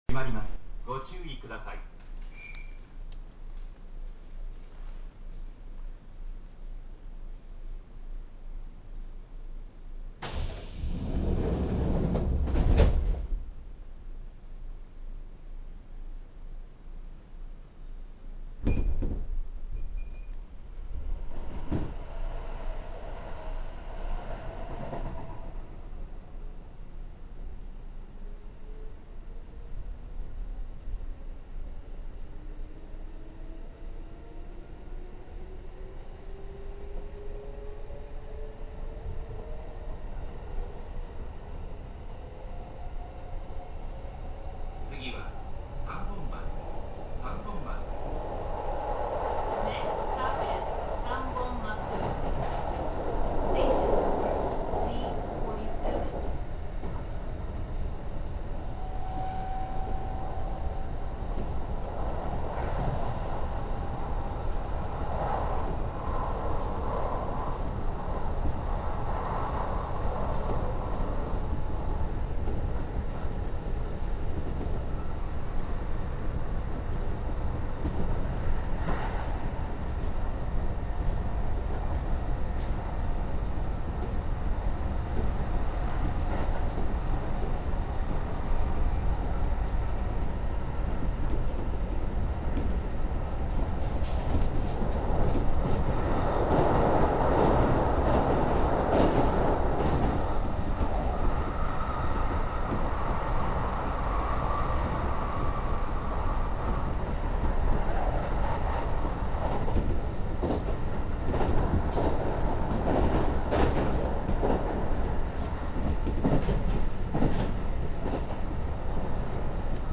・2610系走行音
【大阪線】赤目口→三本松（3分44秒：1.71MB）